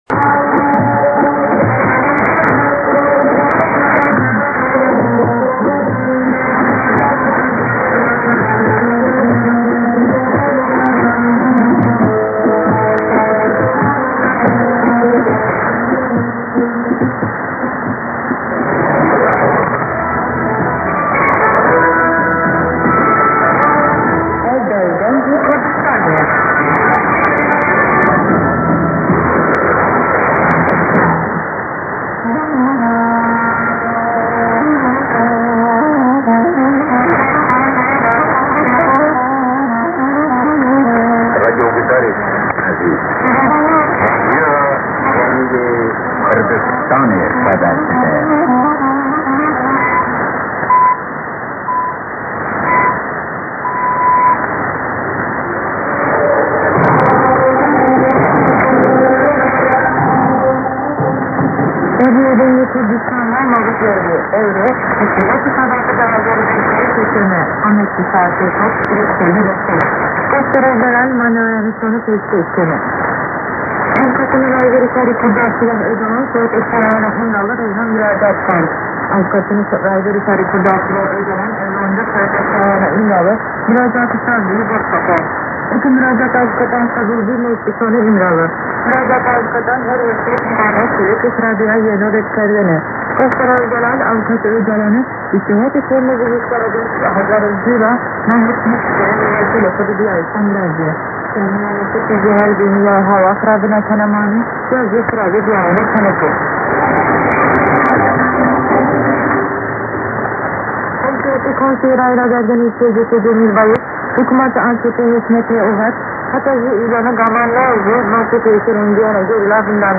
ID: identification announcement